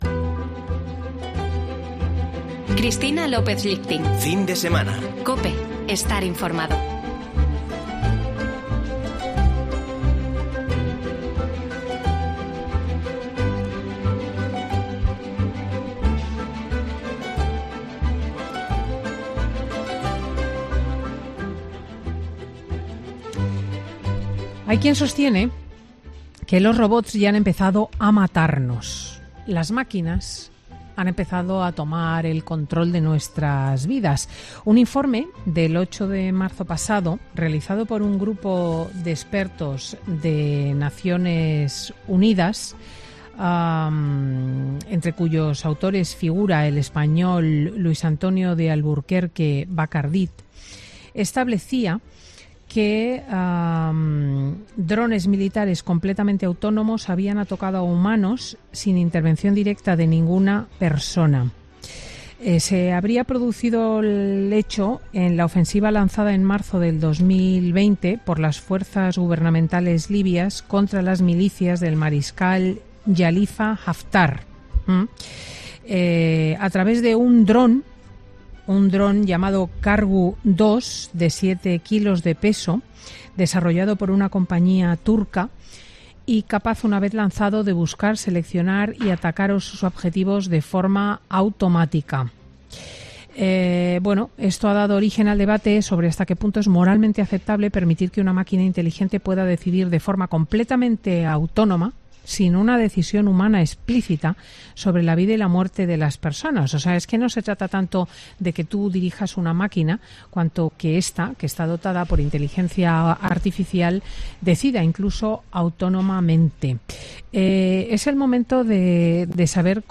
Tertulia de chicos: ¿estamos a punto de ver robots asesinos?